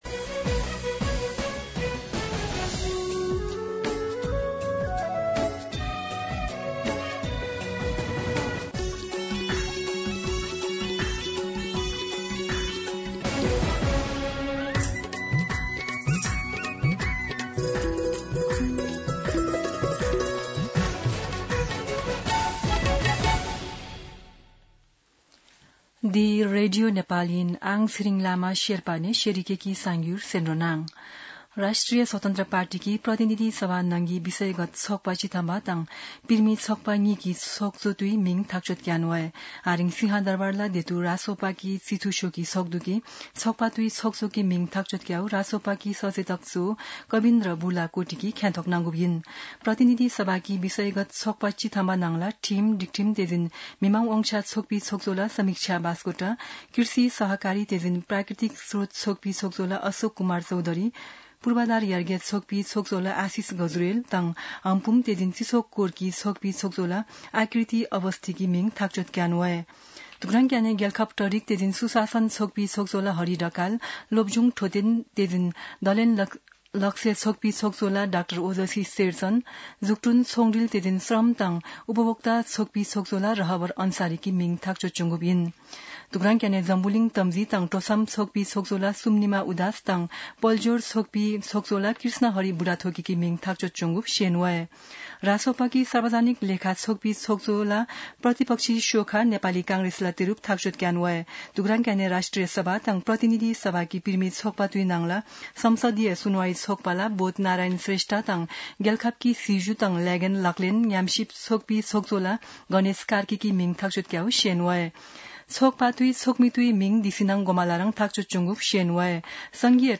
An online outlet of Nepal's national radio broadcaster
शेर्पा भाषाको समाचार : ३ वैशाख , २०८३
Sherpa-News-2.mp3